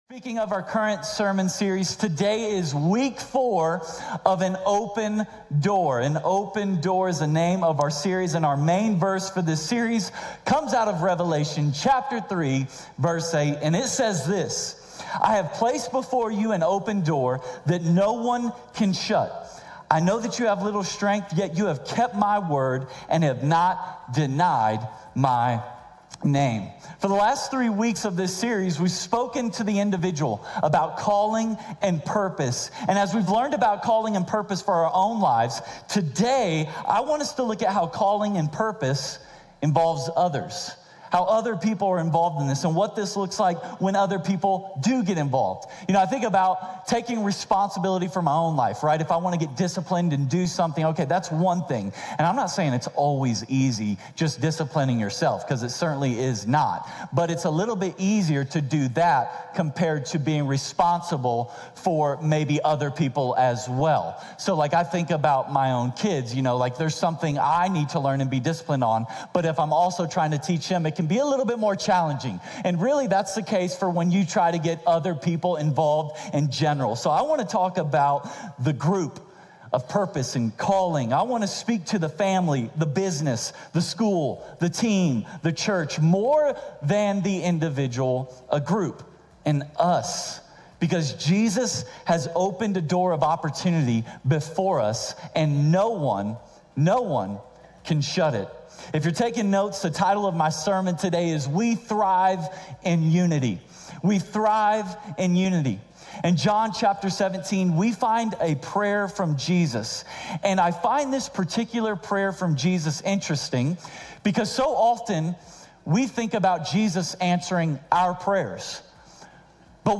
A message from the series "Here Comes The Dreamer." How do you respond when your destiny arrives?